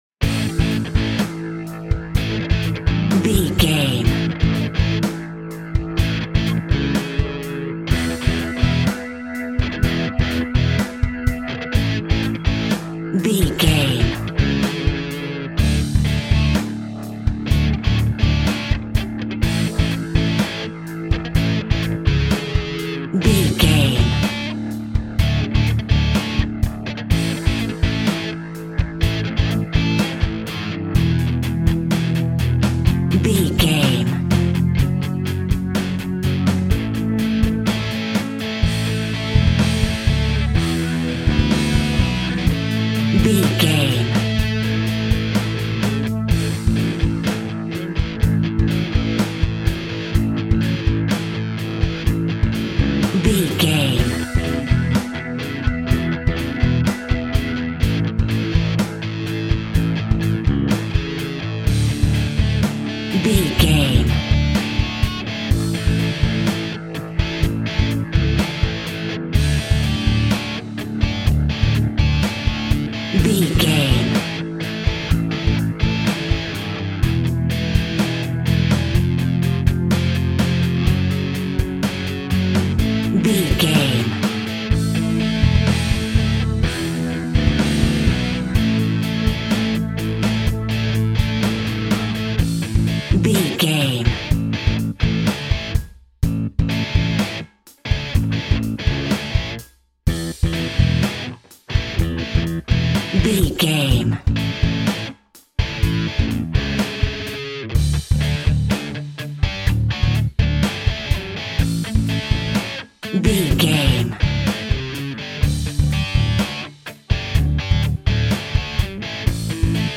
Ionian/Major
energetic
driving
heavy
aggressive
electric guitar
bass guitar
drums
heavy metal
heavy rock
distortion
hard rock
Instrumental rock